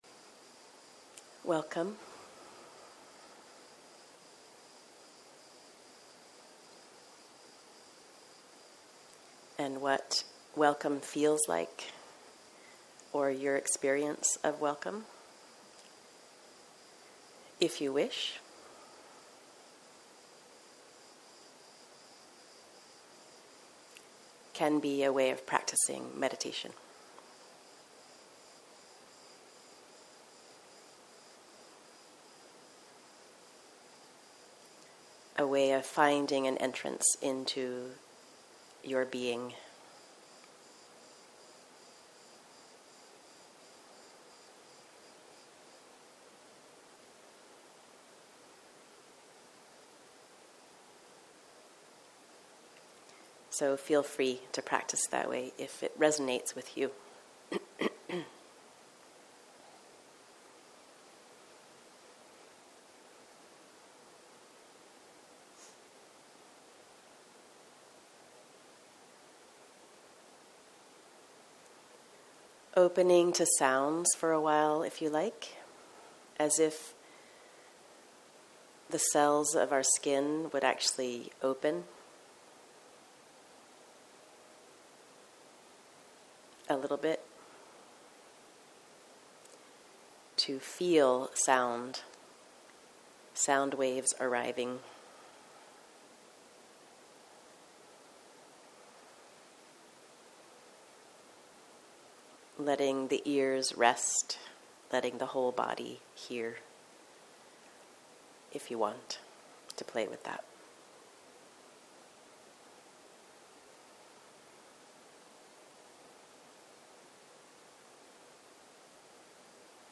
בוקר - מדיטציה מונחית + שאלות ותשובות